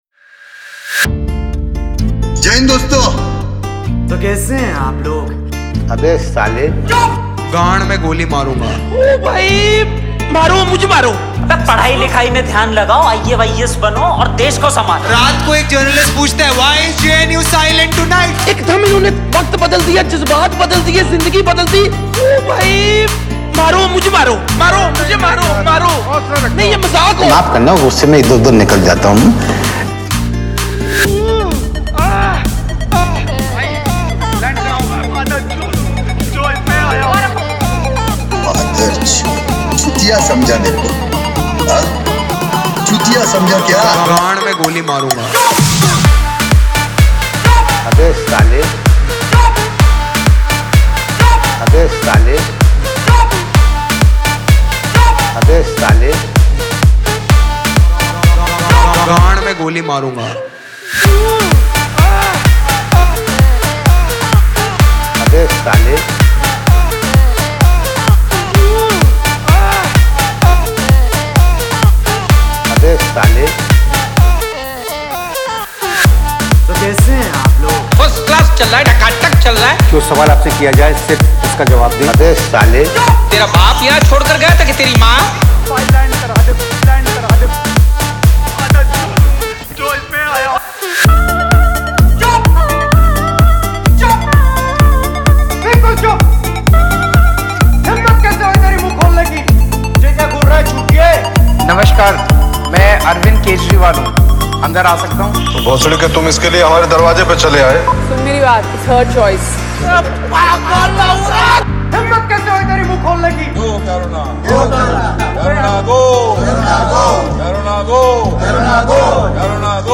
Category : Bollywood DJ Remix Songs